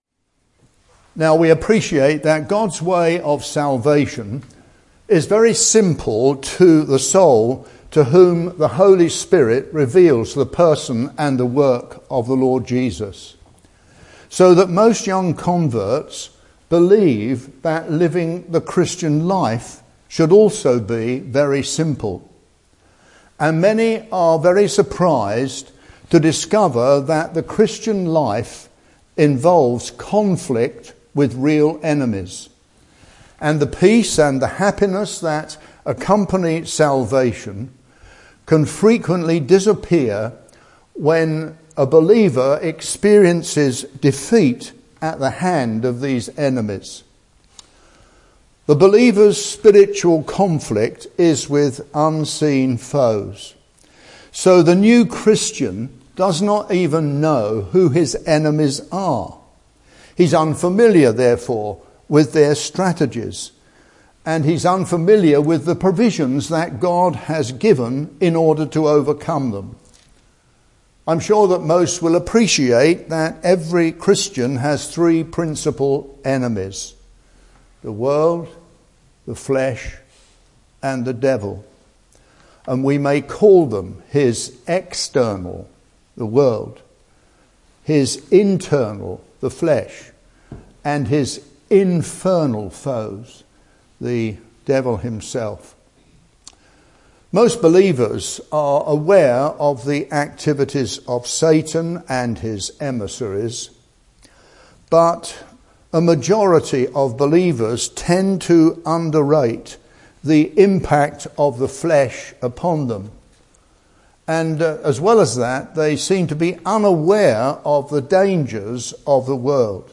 (Message preached 24th May 2018)